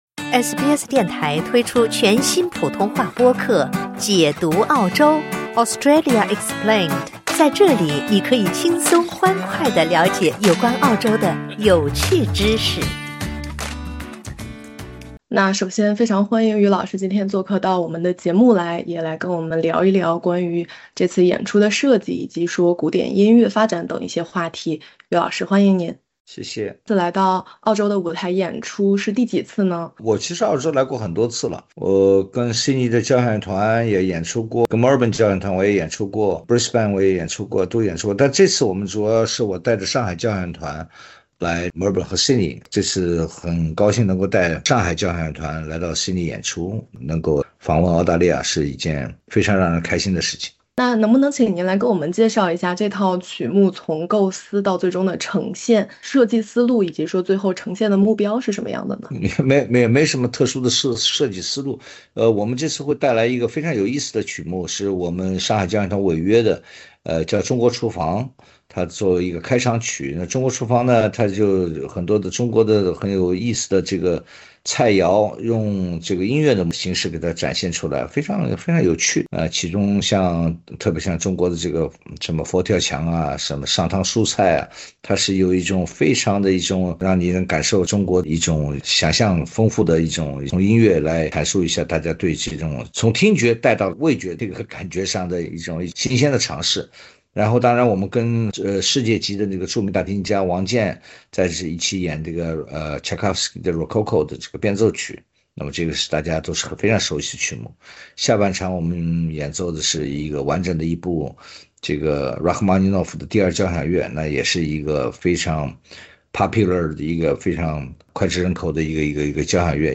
【专访】从当代委约到经典名作：余隆携上海交响乐团3月登台澳洲